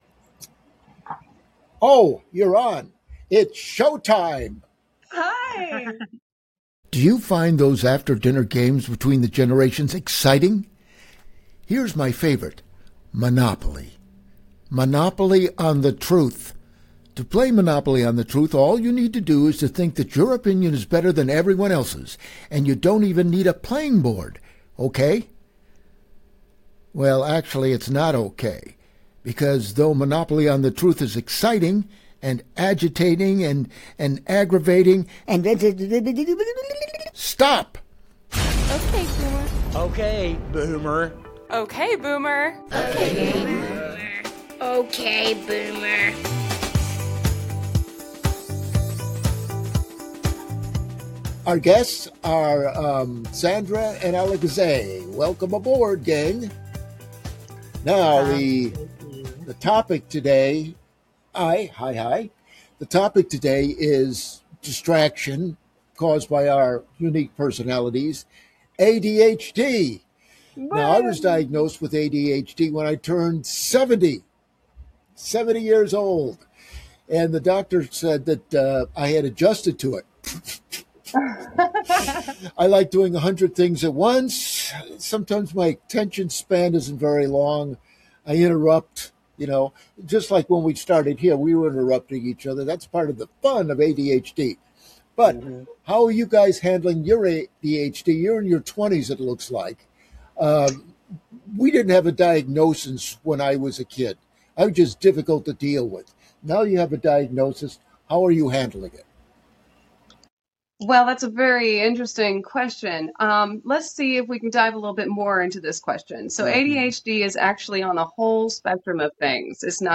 Chaos, laughter, and surprisingly honest conversation.